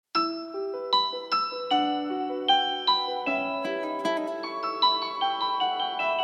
гитара
громкие
спокойные
без слов